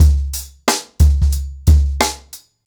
TrackBack-90BPM.7.wav